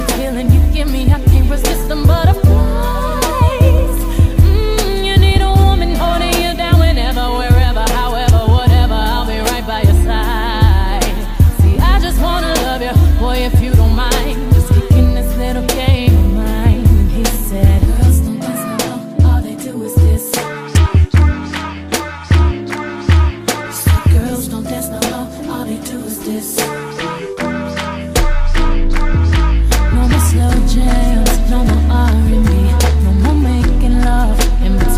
Жанр: Соул / R&b